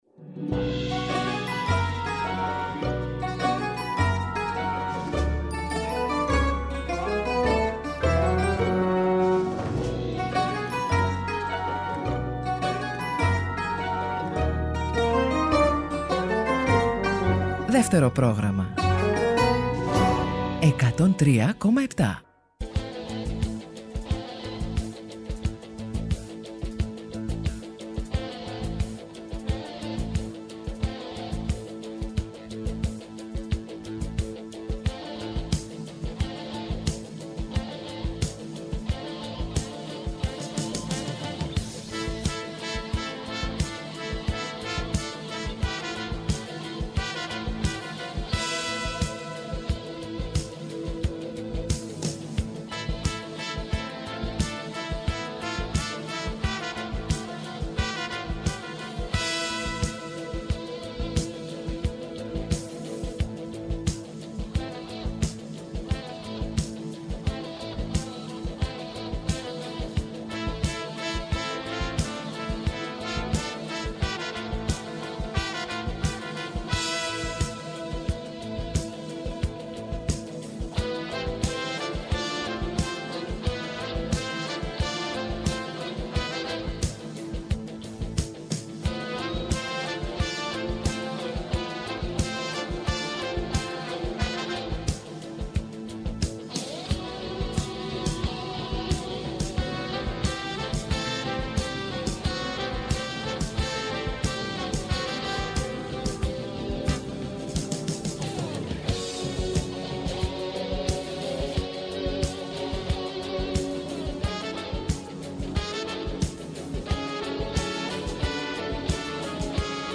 Βόλτες στις μελωδίες, τους ήχους και τους στίχους από όλες τις εποχές του ελληνικού τραγουδιού, διανθισμένες με παρουσιάσεις νέων δίσκων, κινηματογραφικών εντυπώσεων, αλλά και ζεστές κουβέντες με καλλιτέχνες από τη θεατρική επικαιρότητα.